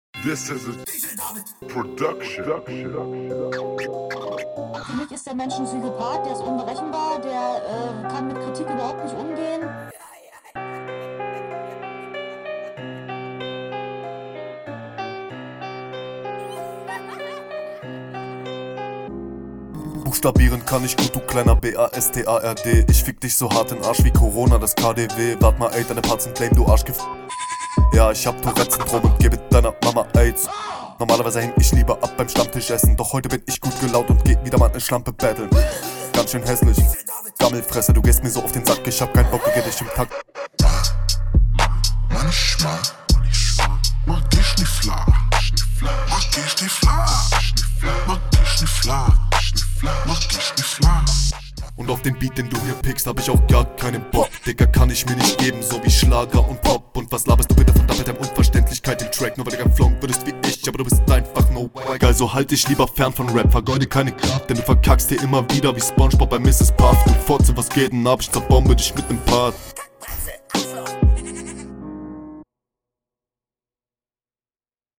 Soundquali, Mische und alle Extras drinnen passen nice rein und hört sich einfach dope an.